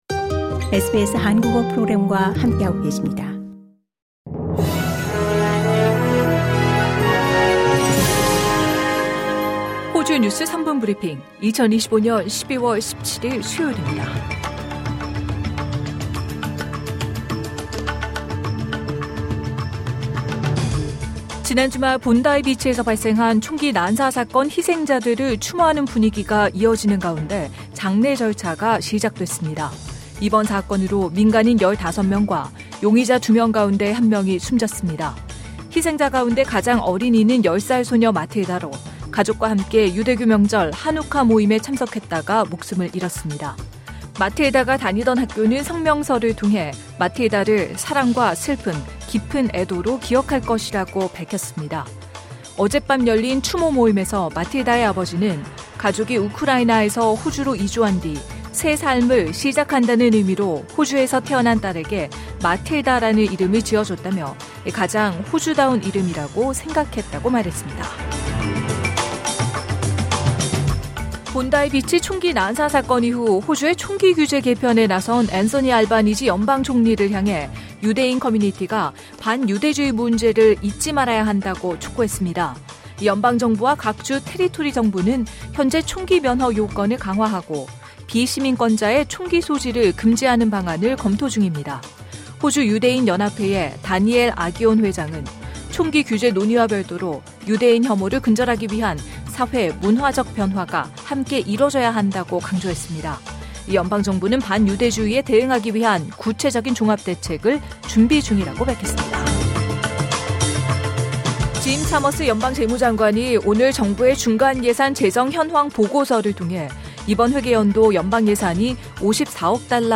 LISTEN TO 호주 뉴스 3분 브리핑: 2025년 12월 17일 수요일 SBS Korean 03:57 Korean 이 시각 간추린 주요 뉴스 지난 주말 본다이에서 발생한 총기 난사 사건 희생자들을 추모하는 분위기가 이어지는 가운데, 장례 절차가 시작됐습니다.